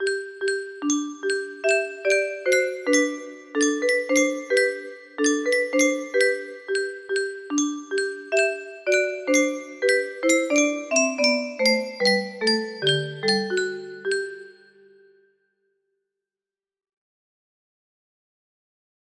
I saw 3 ships harmony music box melody